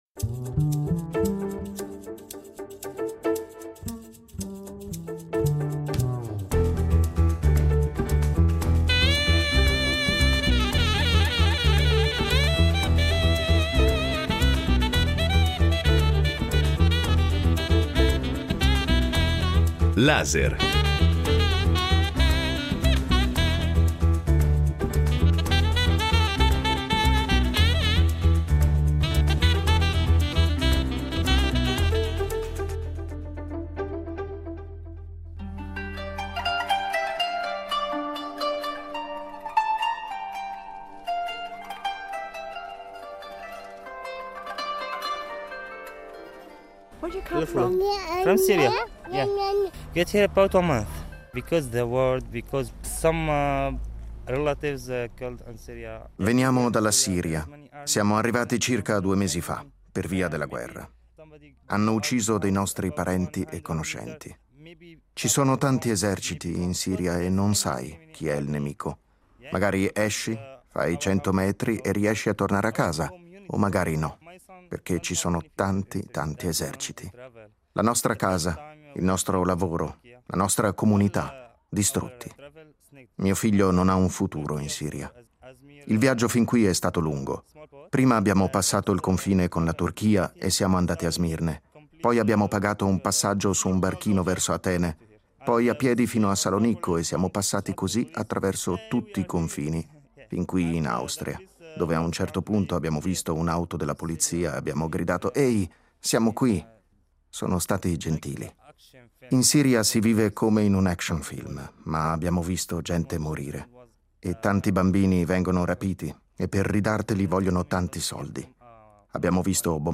Testimonianze dal centro di prima accoglienza di Traiskirchen, prima parte